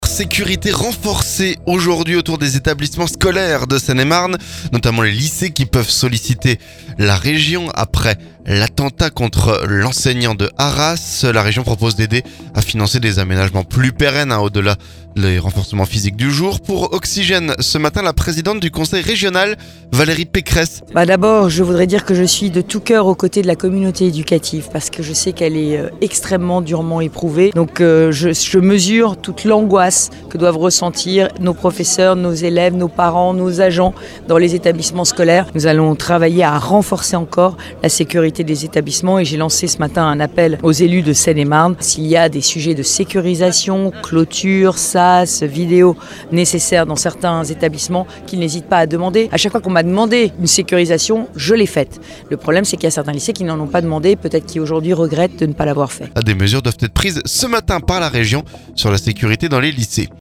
Les lycées peuvent solliciter la Région, qui propose d'aider à financer les aménagements plus pérennes. Pour Oxygène, la Présidente du conseil régional Valérie Pécresse.